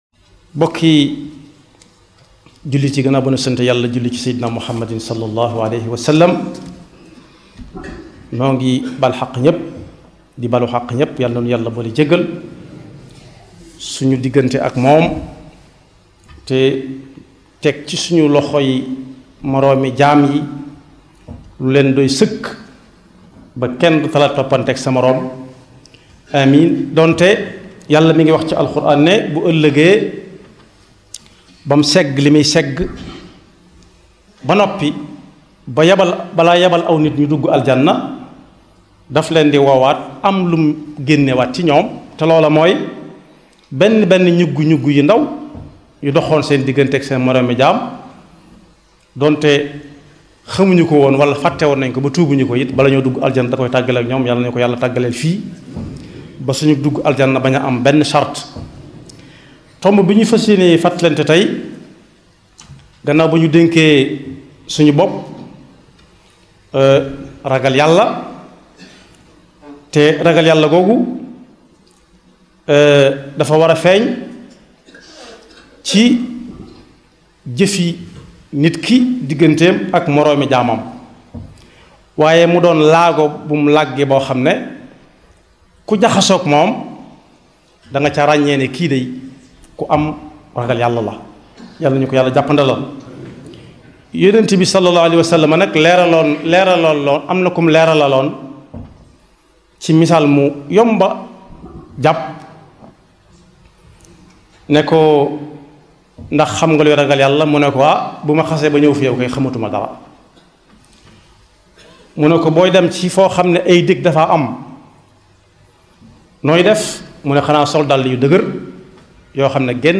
Les Khoutba